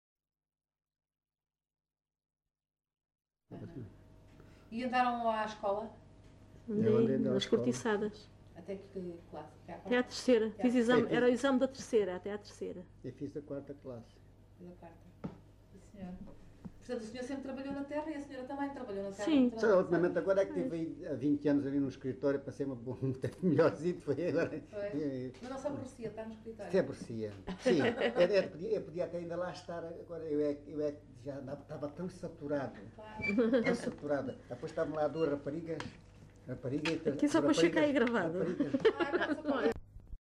LocalidadeLavre (Montemor-o-Novo, Évora)